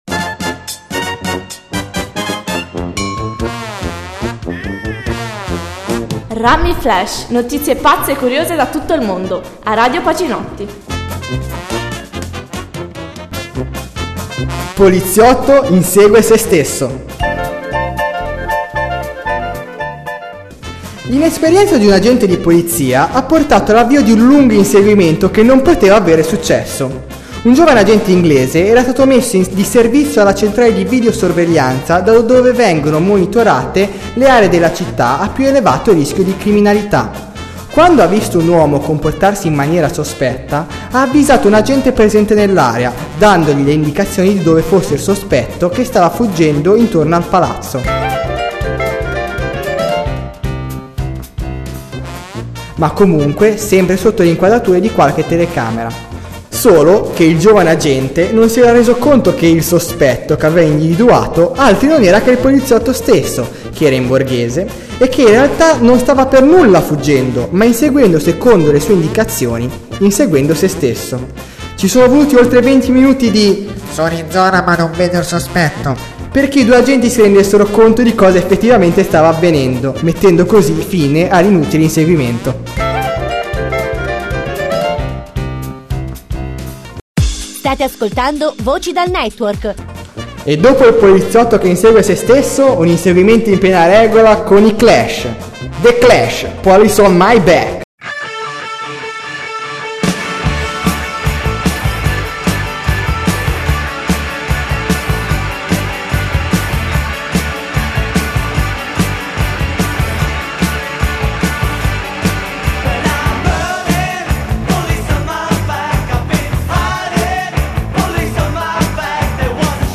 play_circle_filled rummy flash 2 poliziotto fran.mp3 Radioweb Pacinotti si tratta di una serie (praticamente infinita) di trasmissioni brevi da mettere in palinsesto ovunque anche random. Sarebbe meglio farle in diretta, per ora le considero una palestra, ma anche registrate hanno il pregio di poter stare comodamente in frigo ed uscirne alla bisogna. L'argomento è: notizie pazze e curiose, nn può mancare in ogni Emittente che si rispetti, attrae e diverte il pubblico, attira l'attenzione se si inserisce più di un brano tra la trama si ottiene un effetto fidelizzazione creando aspettativa.